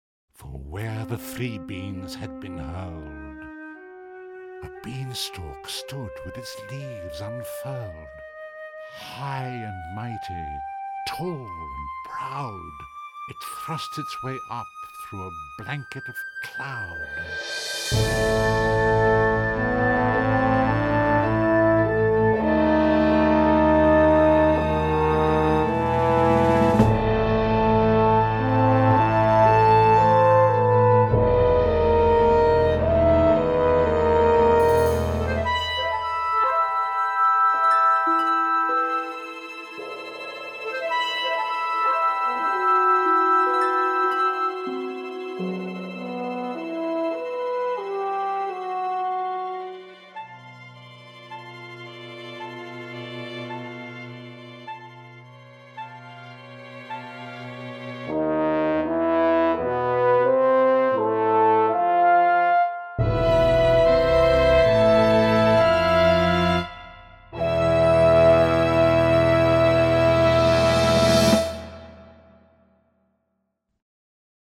For large ensemble and narrator Words